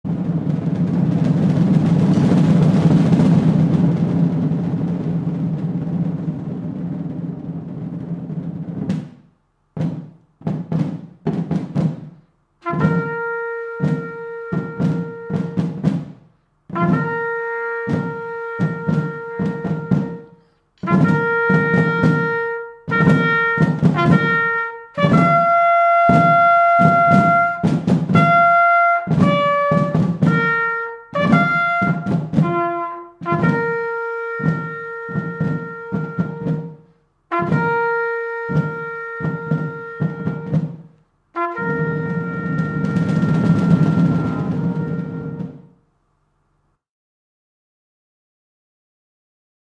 SONNERIE AUX MORTS.mp3